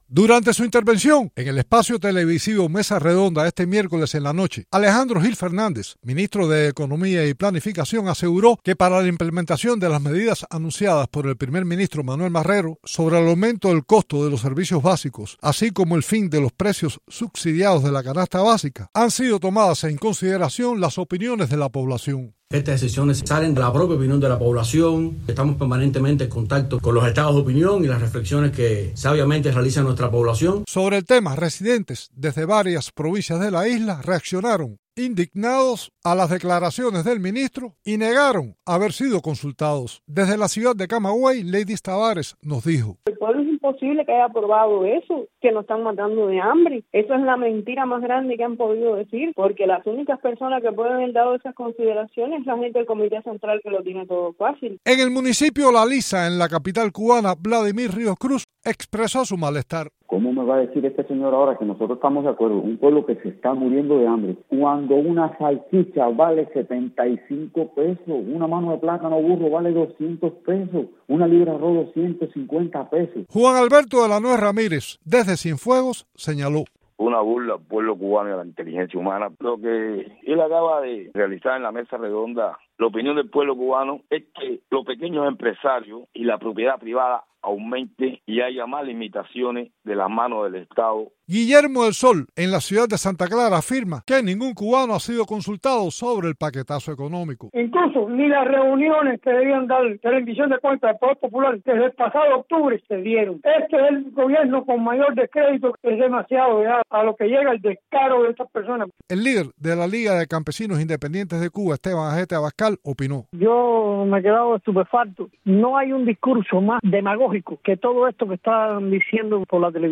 Residentes de varias provincias de la isla entrevistados por Martí Noticias reaccionaron indignados a las declaraciones que hizo en el espacio televisivo Mesa Redonda, el miércoles en la noche, Alejandro Gil Fernández, ministro de Economía y Planificación, alegando que las nuevas medidas restrictivas anunciadas en la Asamblea Nacional del Poder Popular son fruto de demandas del pueblo.